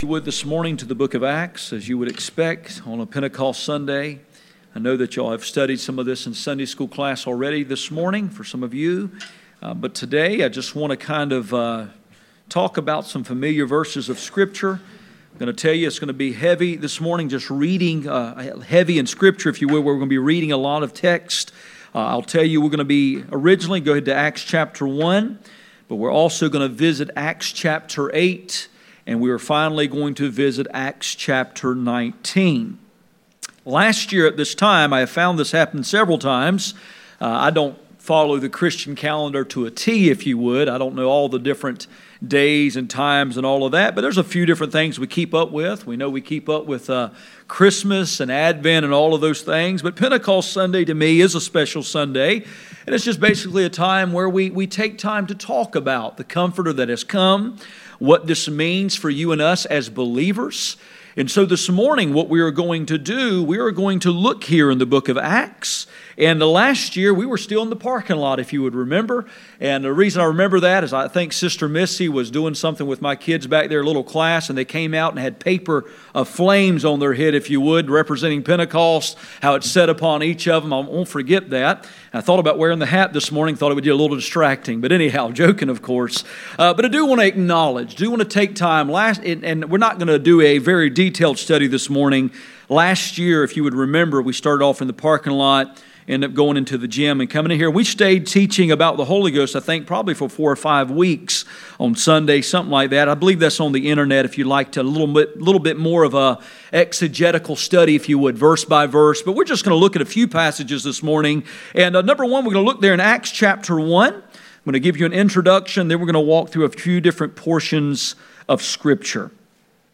Acts 1:8 Service Type: Sunday Morning %todo_render% « The danger of duplicity The need of the Holy Ghost